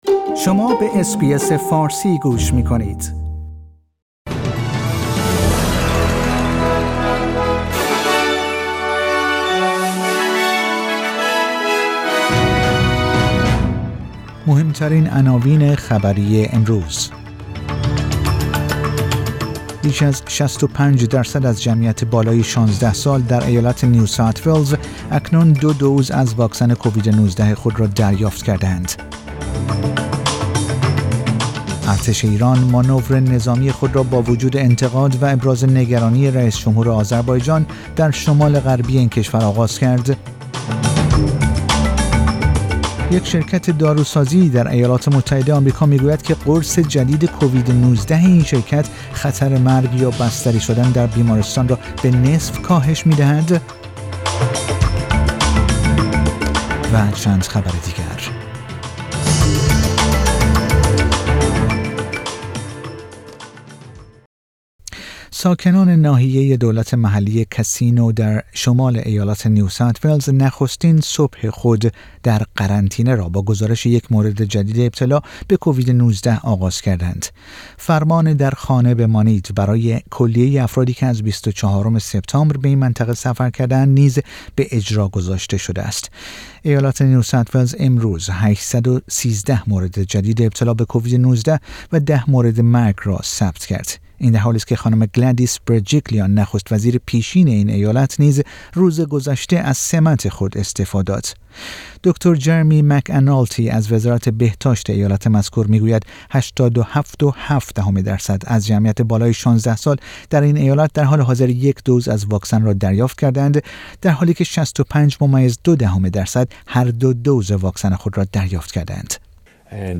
پادکست خبری آخر هفته اس بی اس فارسی